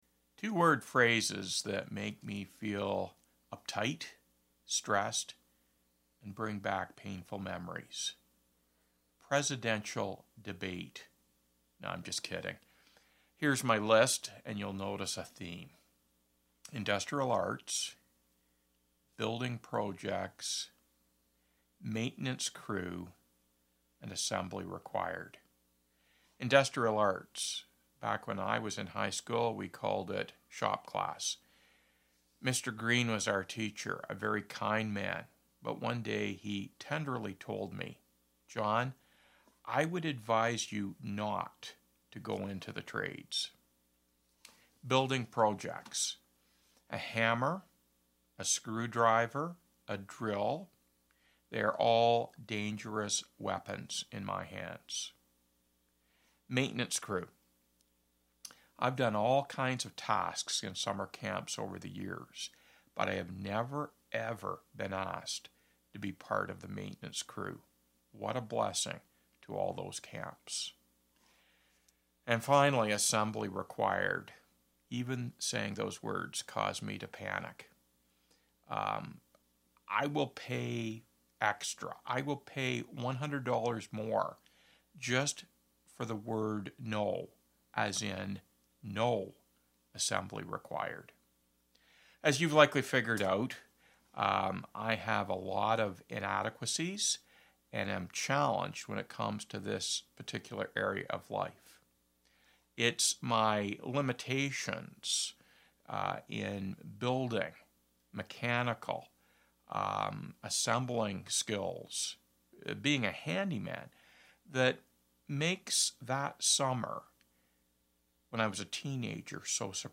Past Sermons - Byron Community Church